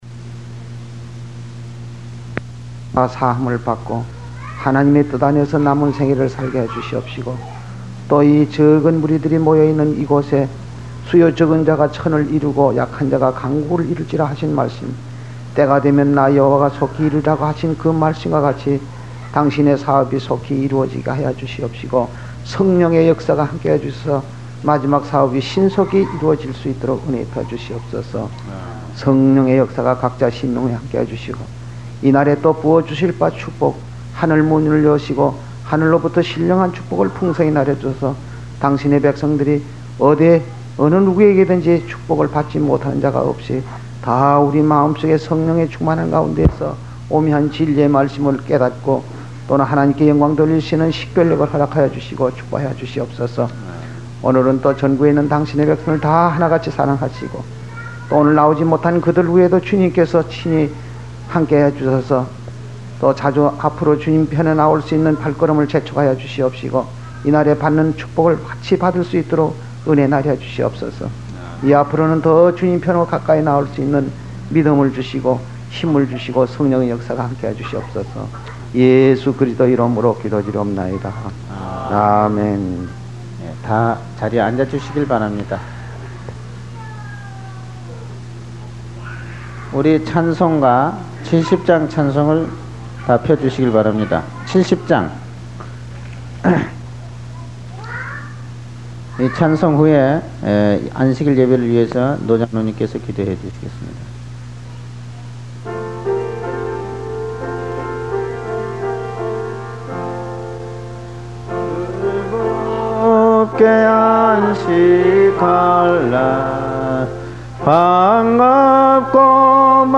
찬송가 70장 즐겁게 안식할날
찬송가 302장 내게있는 모든것을